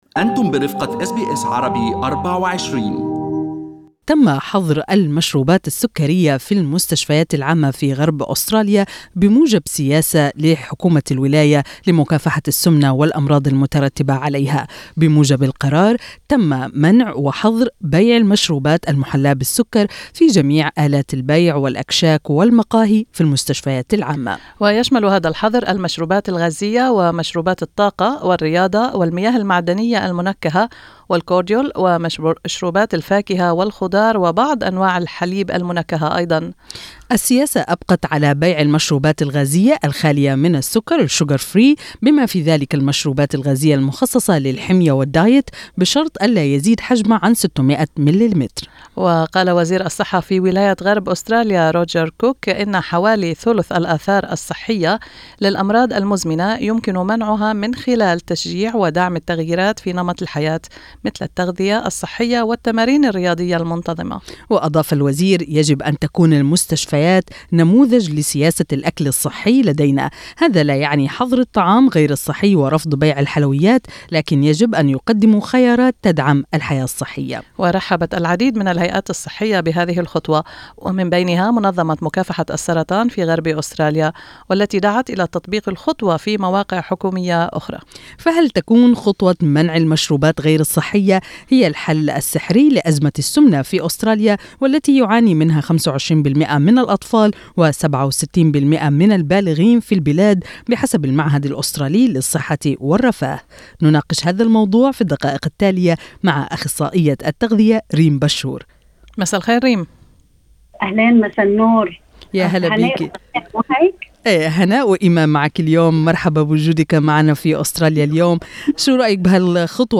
استمعوا إلى اللقاء الكامل مع أخصائية التغذية